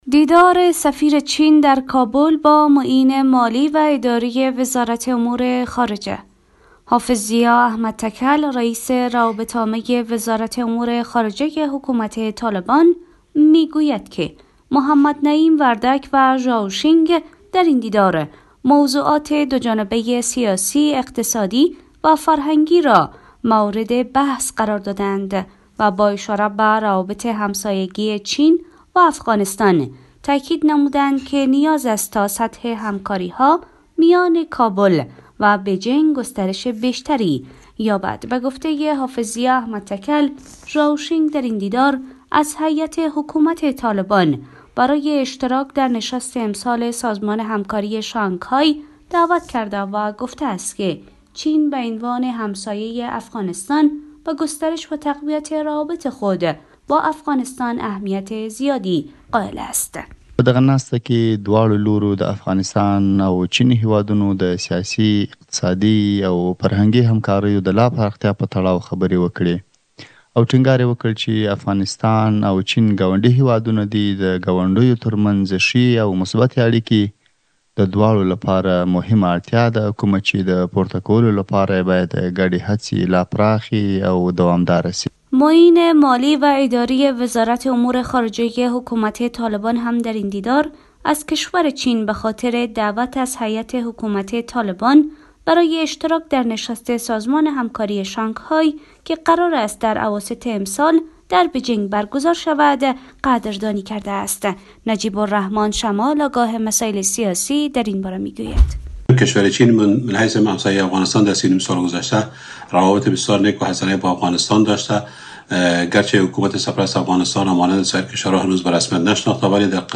خبر